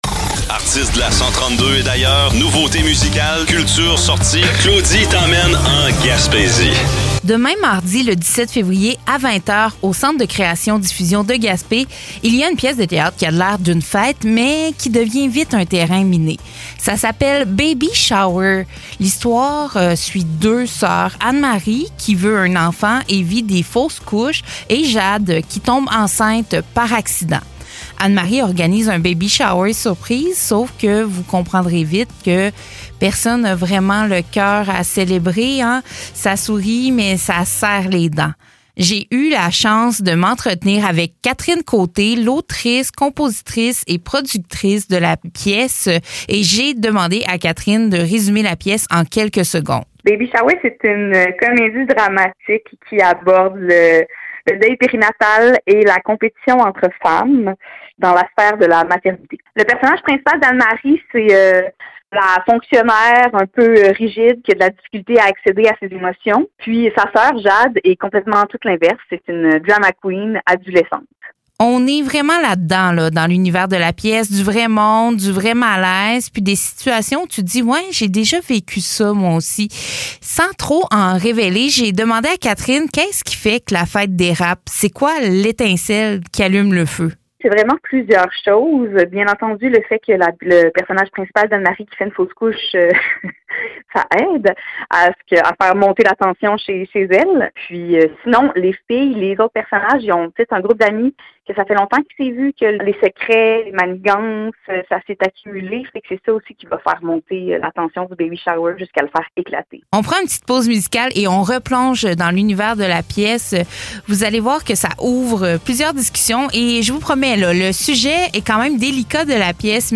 Ce que l’entrevue laisse surtout entendre, c’est ceci : Baby Shower n’est pas un spectacle qui écrase.